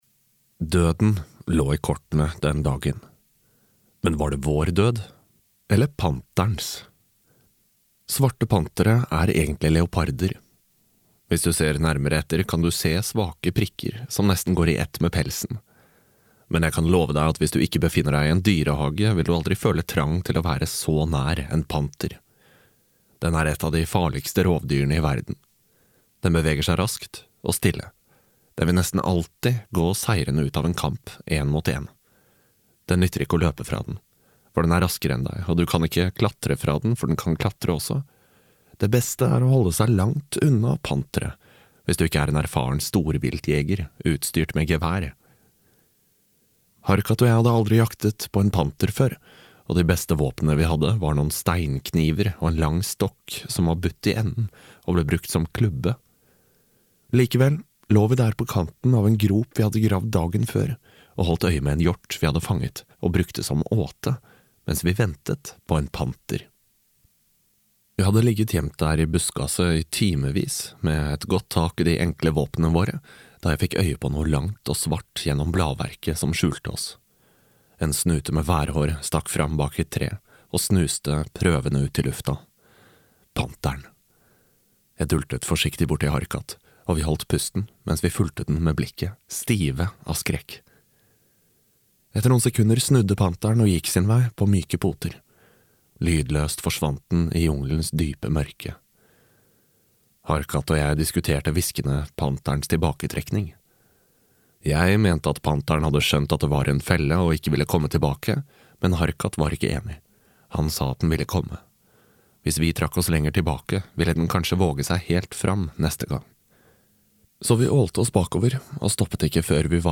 Sjelesjøen (lydbok) av Darren Shan